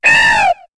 Cri d'Arkéapti dans Pokémon HOME.